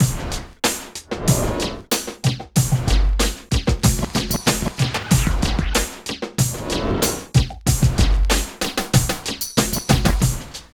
99 LOOP   -R.wav